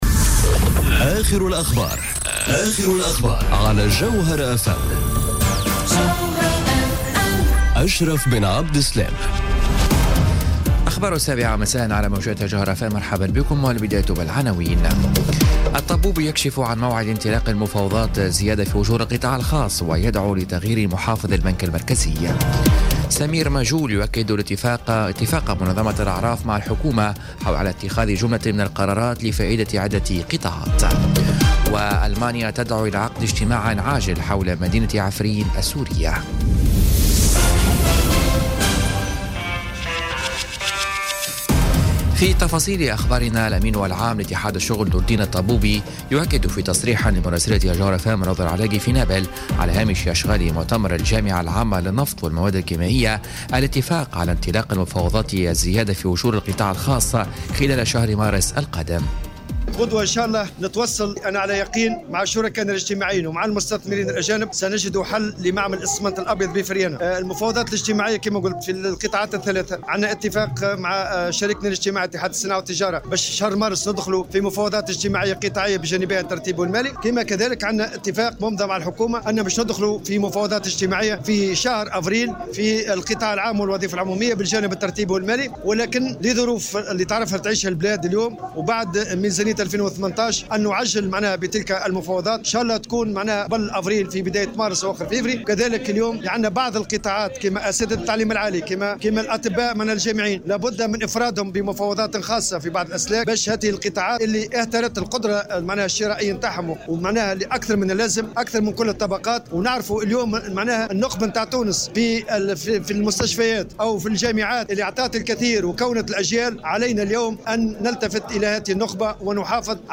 نشرة أخبار السابعة مساءً ليوم الخميس 25 جانفي 2018